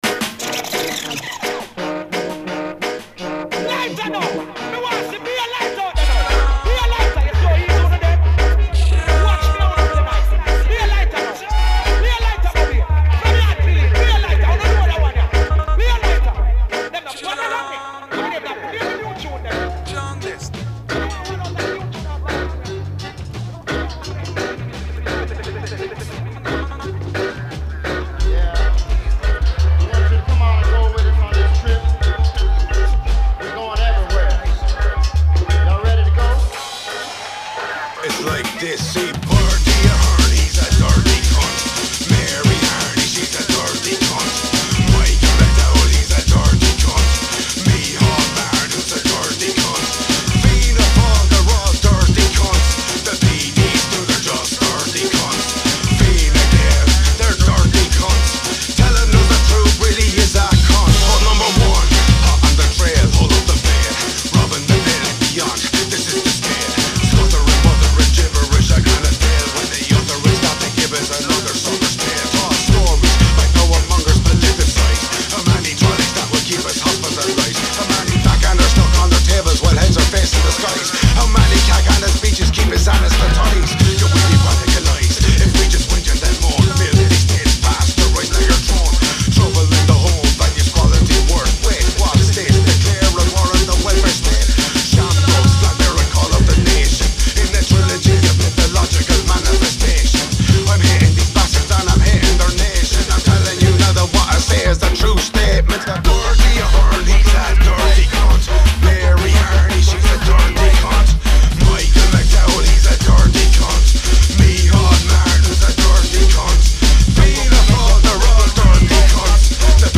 A mix of two Irish tunes –